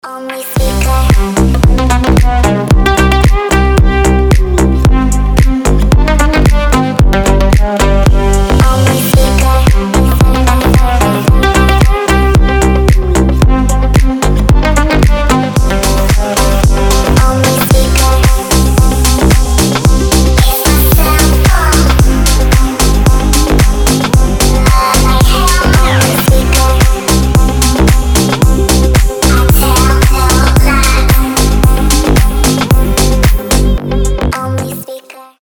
• Качество: 320, Stereo
deep house
восточные мотивы
Electronic
забавный голос